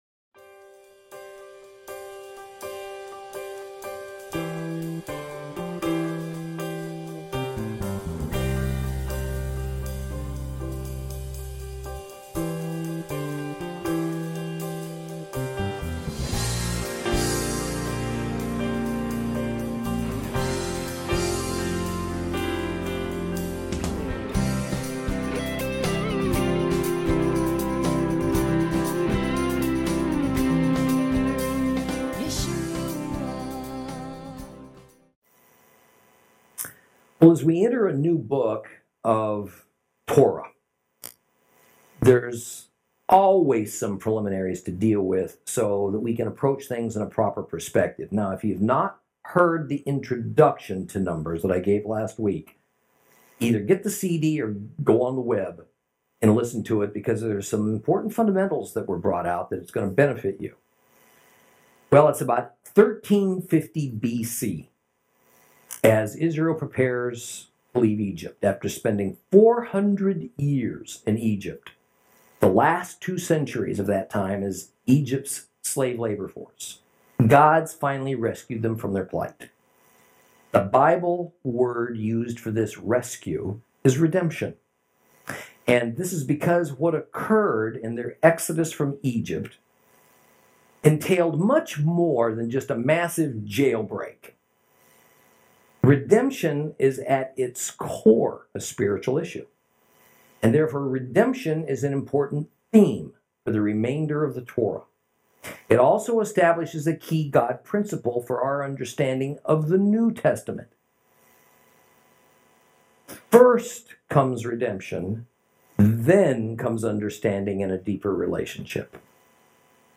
Lesson 2 – Numbers 1